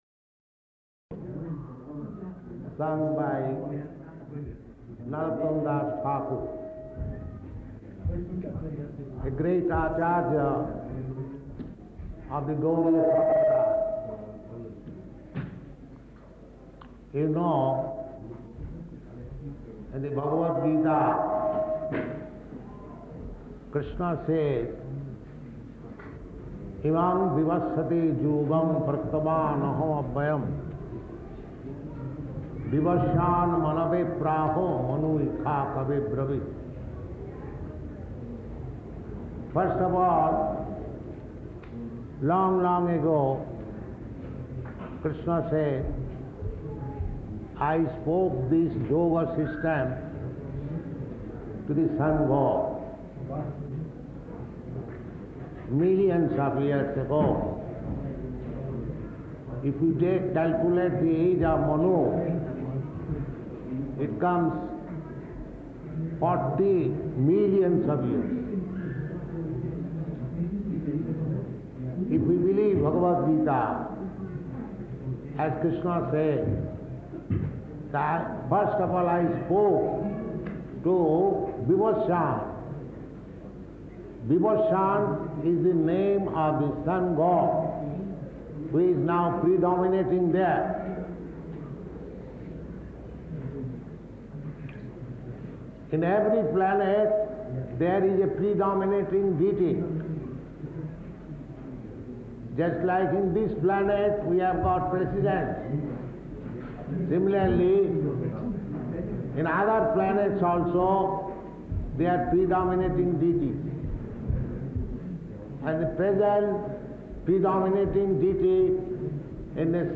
Engagement --:-- --:-- Type: Lectures and Addresses Dated: September 18th 1971 Location: Nairobi Audio file: 710918LE-NAIROBI.mp3 Prabhupada: ...sung by Narottama dāsa Ṭhākura, a great ācārya of the Gauḍīya-sampradāya.